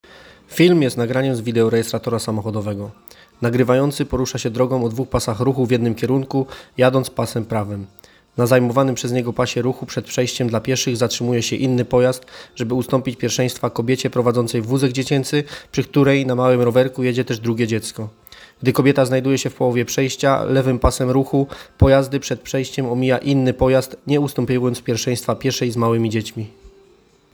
Opis nagrania: audiodeskrypcja nagrania